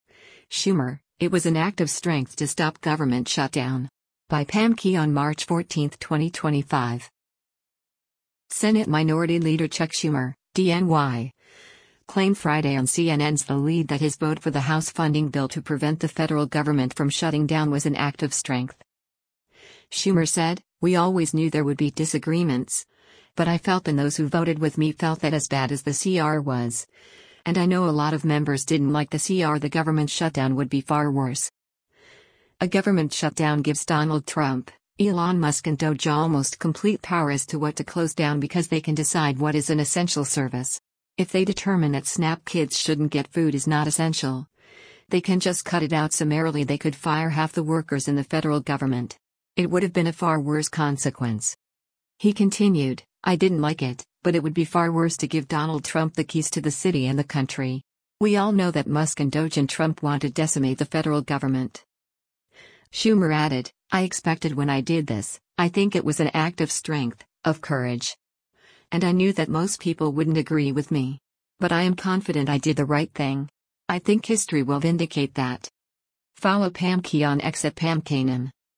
Senate Minority Leader Chuck Schumer (D-NY) claimed Friday on CNN’s “The Lead” that his vote for the House funding bill to prevent the federal government from shutting down was an “act of strength.”